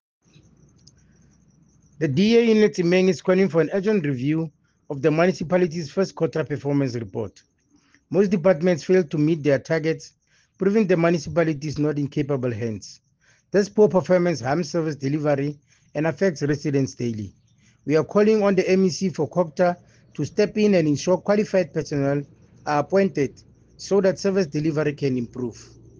English soundbite by Cllr Thabo Nthapo,